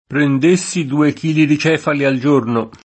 prend%SSi due k&li di ©$fali al J1rno!] (Moretti) — sim. il pers. m. stor. Cefalo e i cogn. Cefali, Cefalo